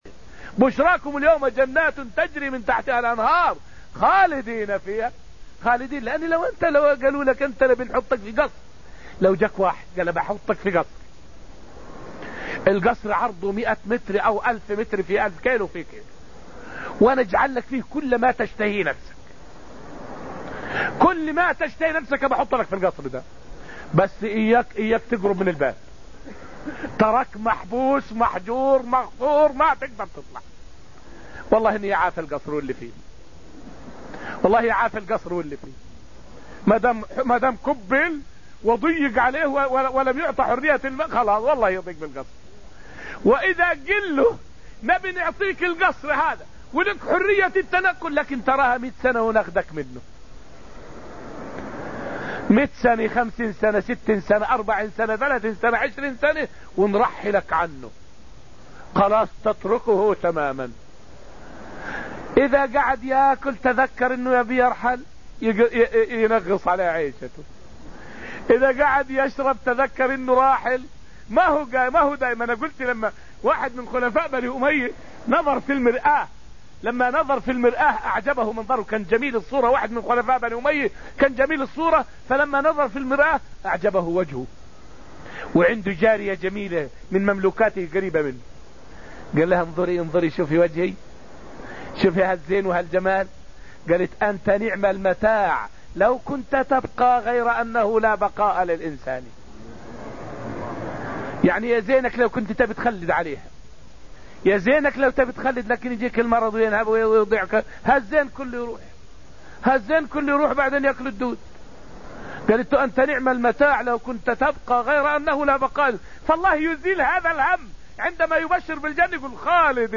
فائدة من الدرس الرابع عشر من دروس تفسير سورة الحديد والتي ألقيت في المسجد النبوي الشريف حول خلود أهل الجنة في النعيم.